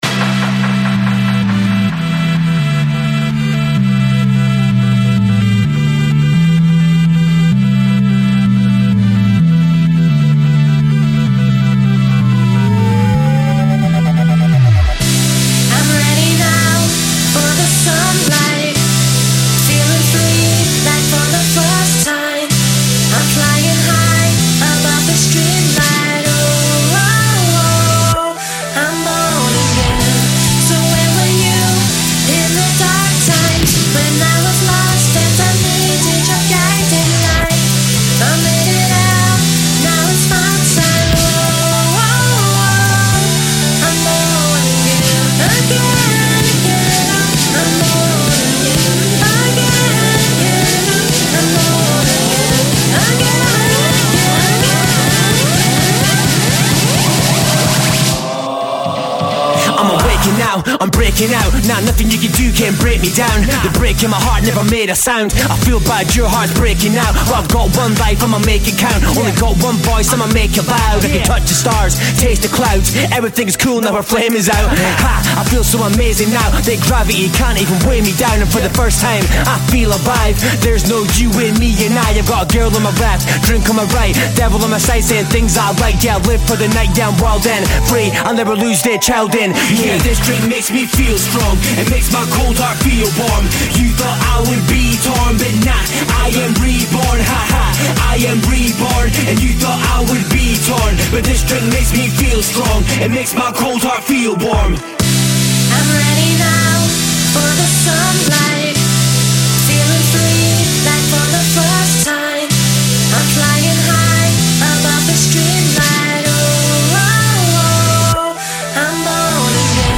singing their hit single